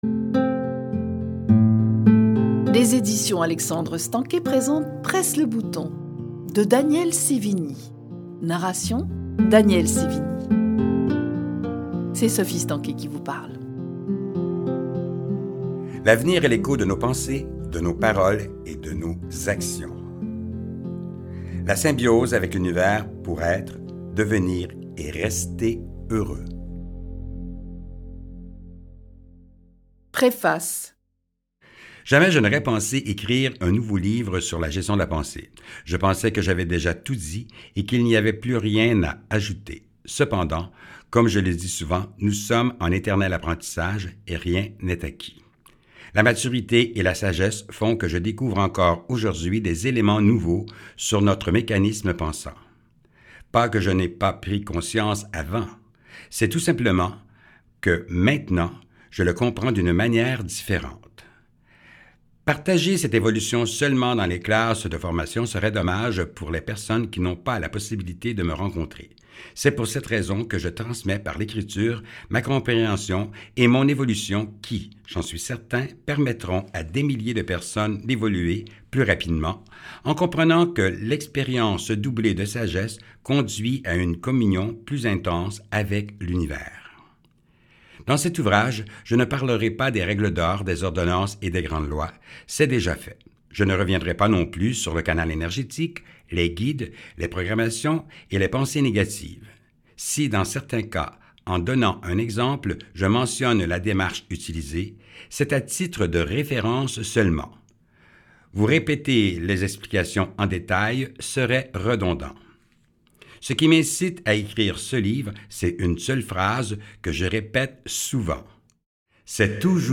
Grâce à ce livre audio, vous ouvrirez les portes de la réussite, de la réalisation et du bonheur.